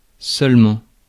Ääntäminen
Ääntäminen France (Paris): IPA: [sœl.mɑ̃] Haettu sana löytyi näillä lähdekielillä: ranska Käännös Konteksti Ääninäyte Adverbit 1. only US 2. solely US 3. merely UK US Konjunktiot 4. but vanhentunut UK stressed US Luokat Adverbit